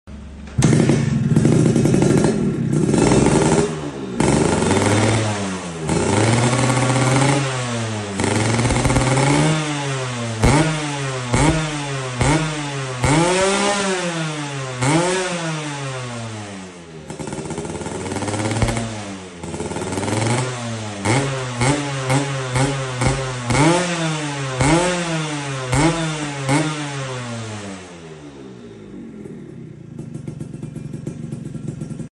Spesial Full Chrom. Knalpot SMR sound effects free download